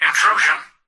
"Intrusion" excerpt of the reversed speech found in the Halo 3 Terminals.